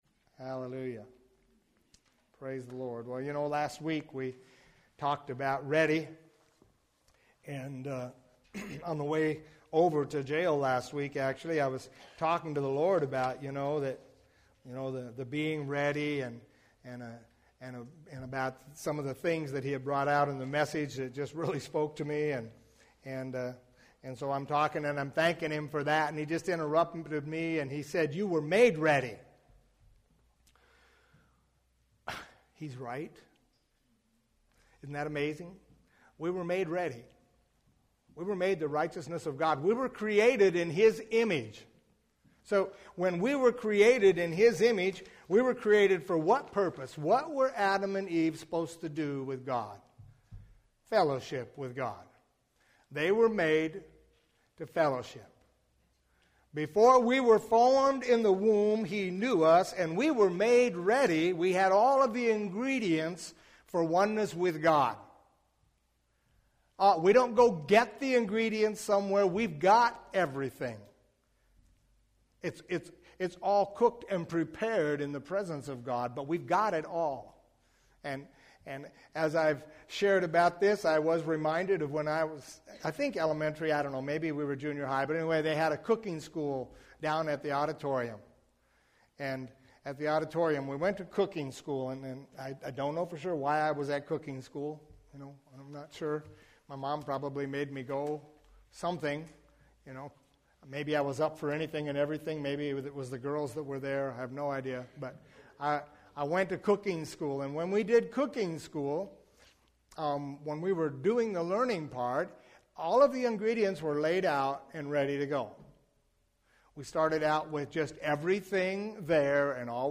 Here you’ll find a selection of audio recordings from Hosanna Restoration Church.
Longer sermons are broken up into smaller...